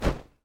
Here’s all 3 of the shirt cloth sounds from the video.